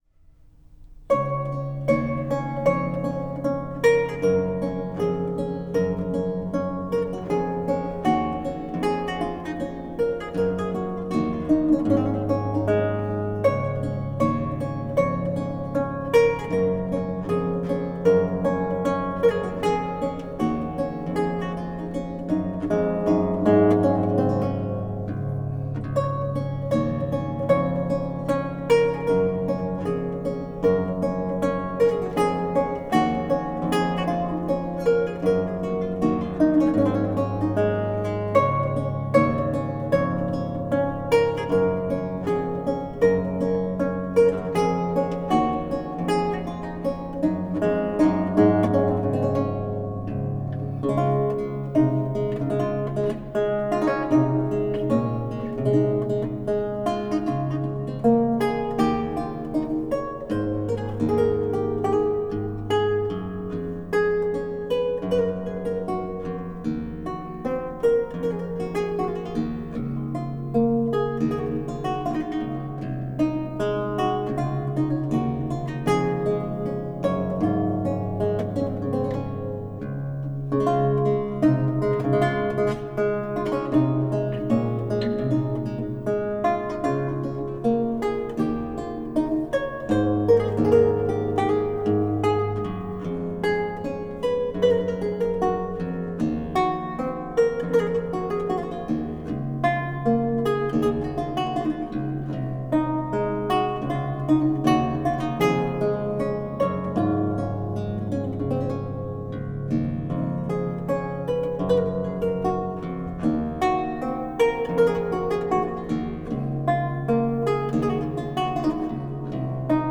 luth baroque